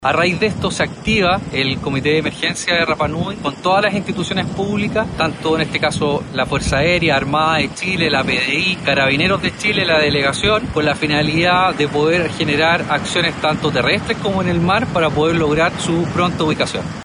Carabineros de la Sexta Comisaría de Rapa Nui, ante la contingencia por la búsqueda de una persona extraviada, realizaron recorridos preventivos y de rastreo en el sector de Hotu Iti, en conjunto con personal de la Armada, Mau Henua y la municipalidad del lugar. Así lo comunicó el coronel Rodrigo Troncoso.